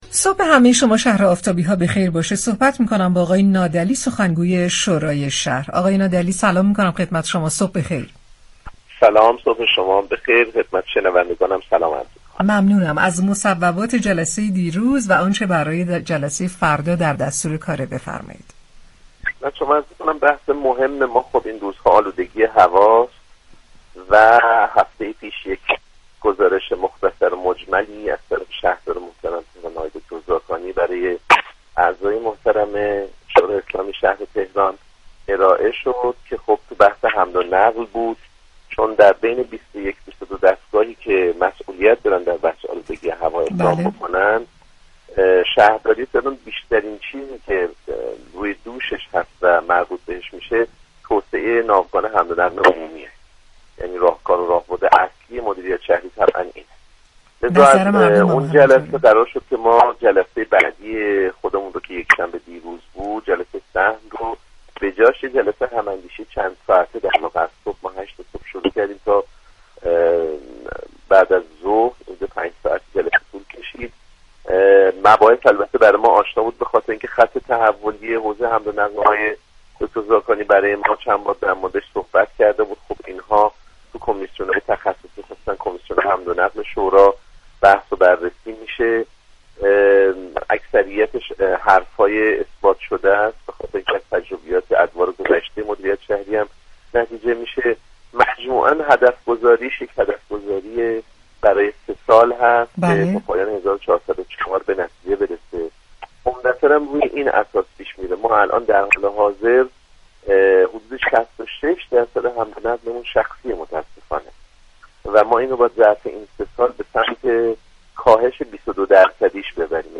به گزارش پایگاه اطلاع رسانی رادیو تهران، علیرضا نادعلی سخنگوی شورای شهر تهران در گفت و گو با «شهر آفتاب» رادیو تهران گفت: اصلی ترین وظیفه شهرداری تهران برای مقابله با آلودگی هوای پایتخت گسترش ناوگان حمل و نقل همگانی است.